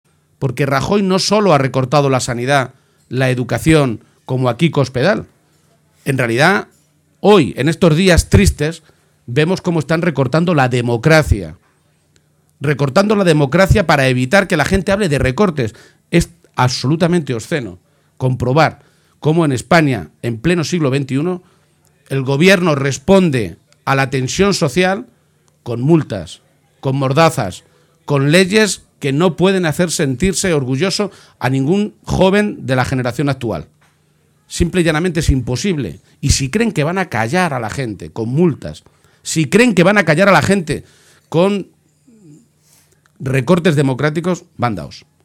Audio Page acto JSE en Albacete-2